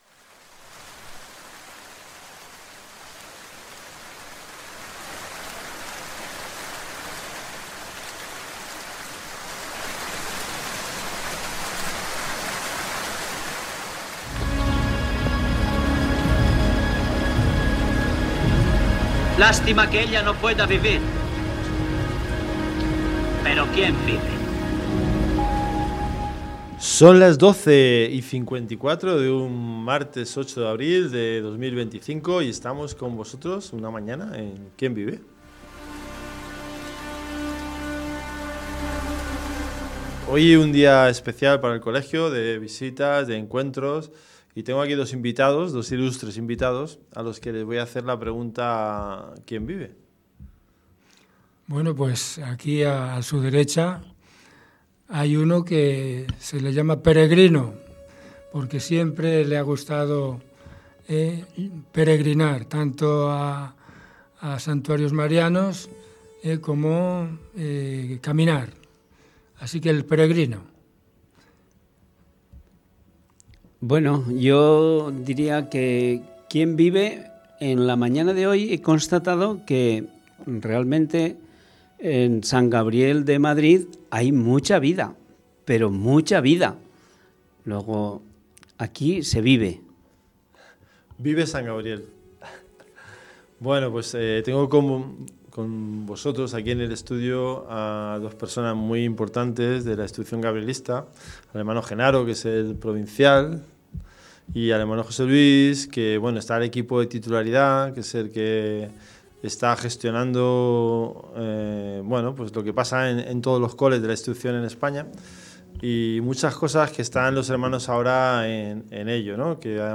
En el programa de hoy tenemos la fortuna de compartir una conversación muy especial con dos invitados de referencia en nuestra comunidad educativa